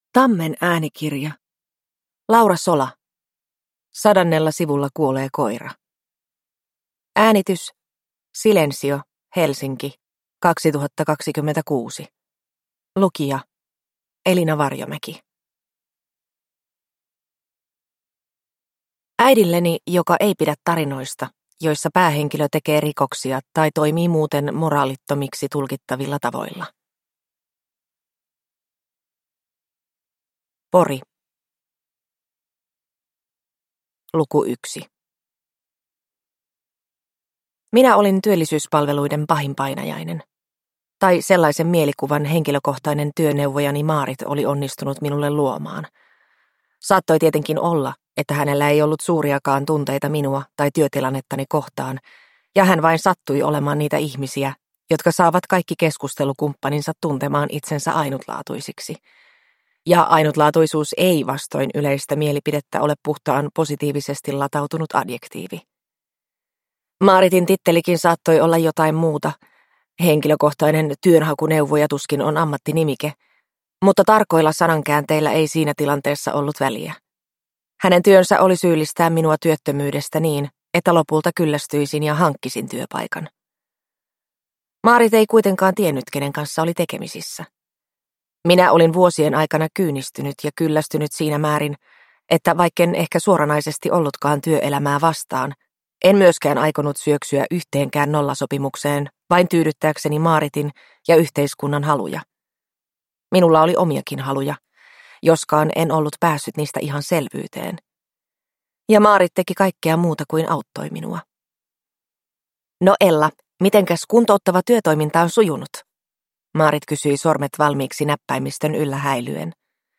Sadannella sivulla kuolee koira – Ljudbok